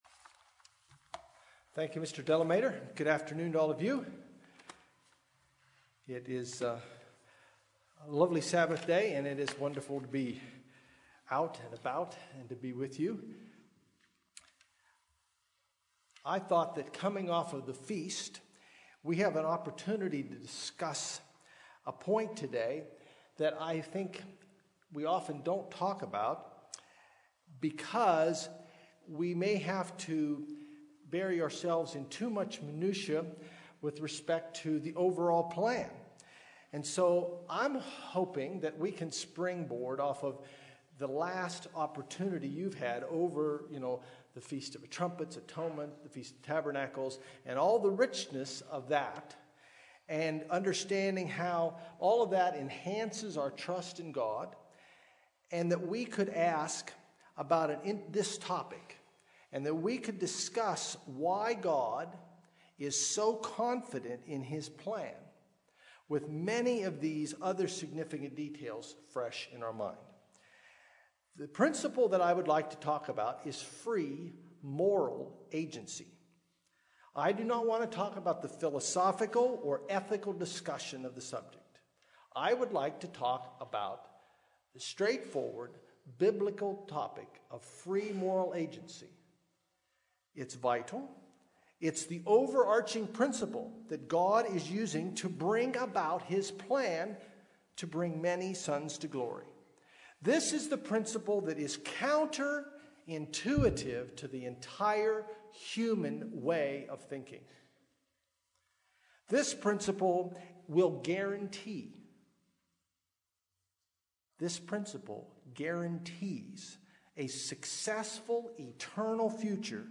Given in Los Angeles, CA
UCG Sermon Studying the bible?